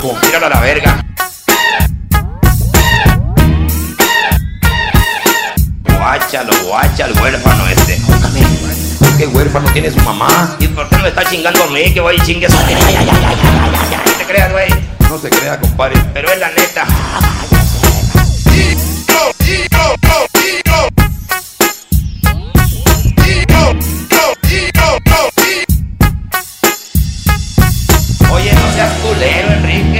Tonos EFECTO DE SONIDO DE AMBIENTE de AY YA VERGAAY YA VERGA